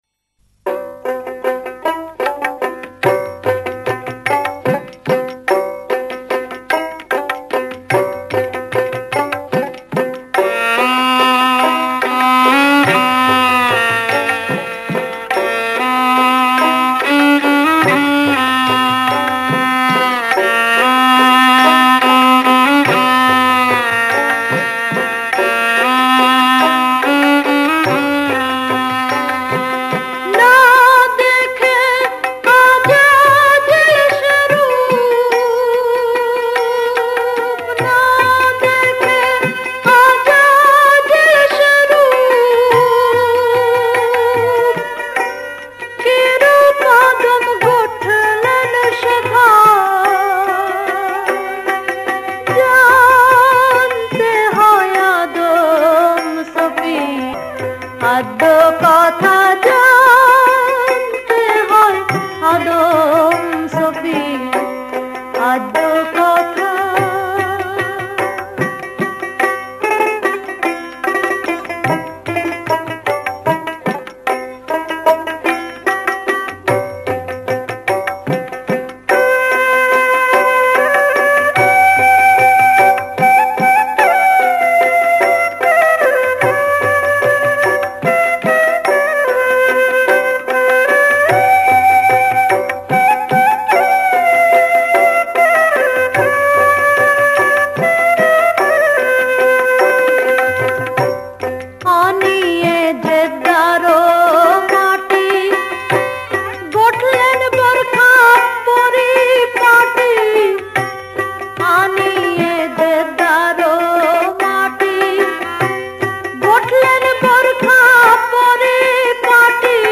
Bengali Kalam